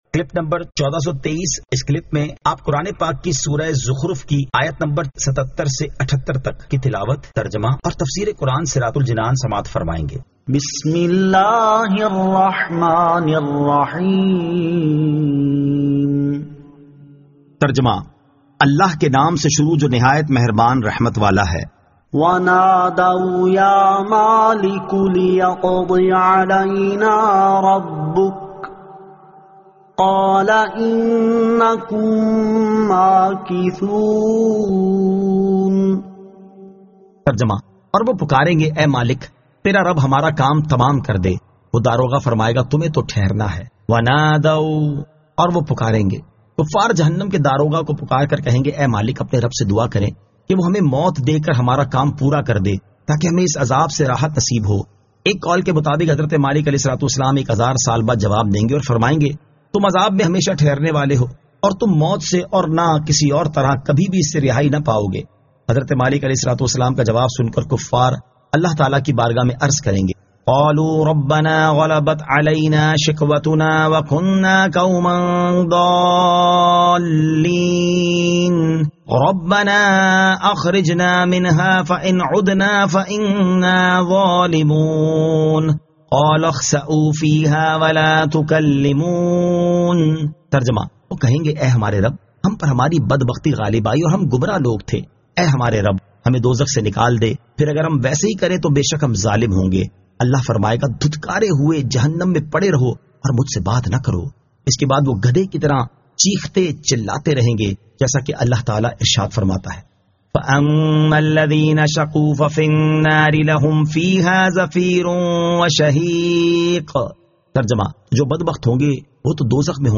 Surah Az-Zukhruf 77 To 78 Tilawat , Tarjama , Tafseer